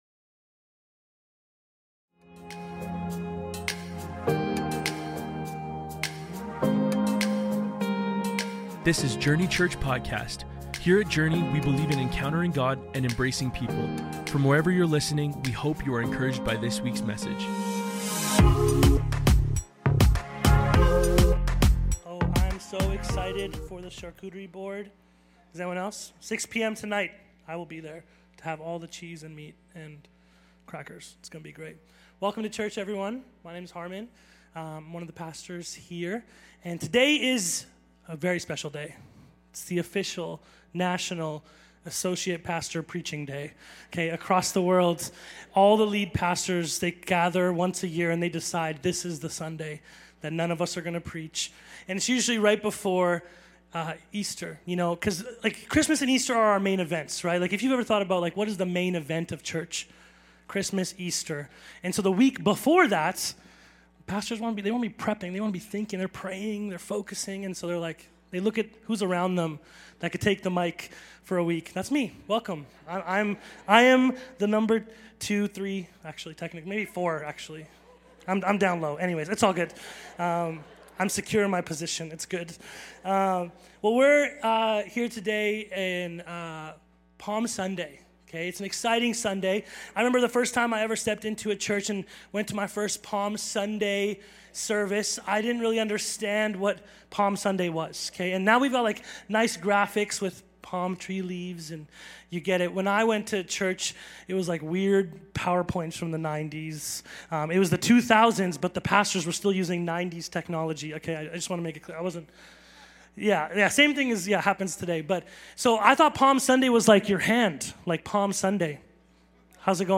It's Palm Sunday!! The day Jesus triumphantly returned to Jerusalem!!